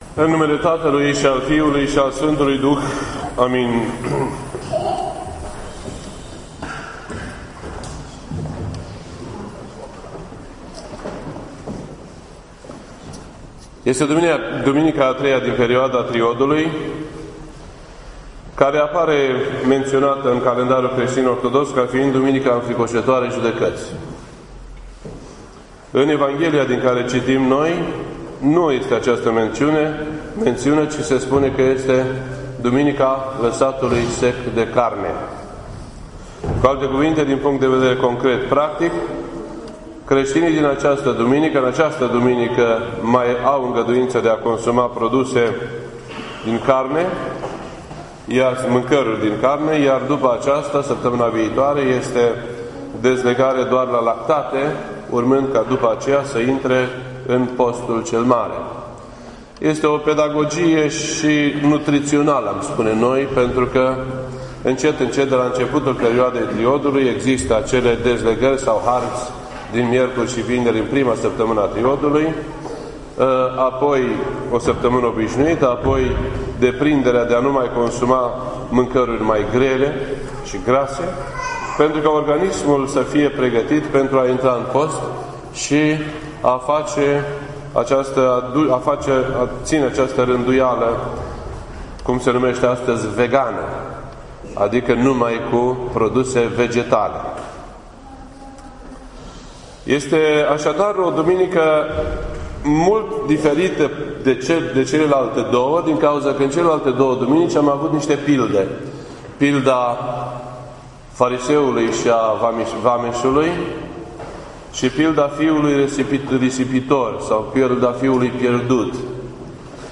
This entry was posted on Sunday, February 19th, 2017 at 8:48 PM and is filed under Predici ortodoxe in format audio.